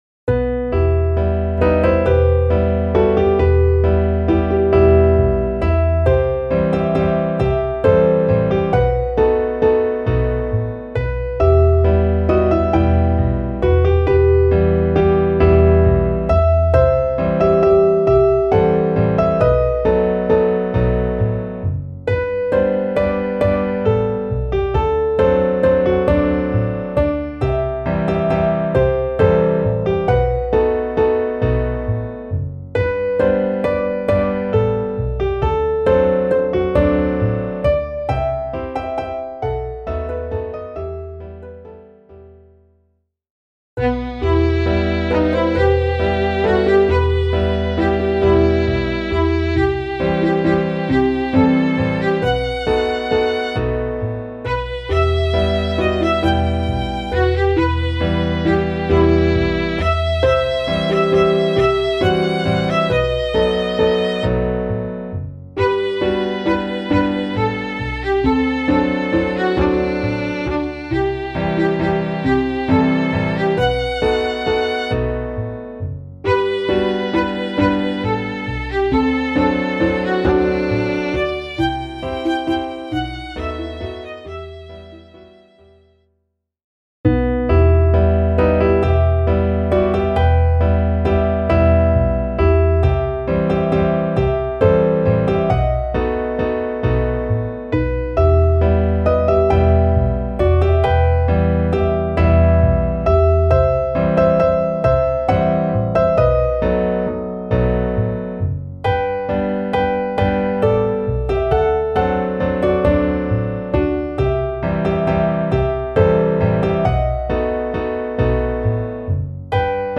Klaviersätze F bis H